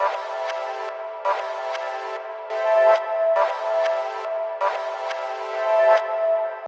Tag: 144 bpm Trap Loops Synth Loops 1.12 MB wav Key : Unknown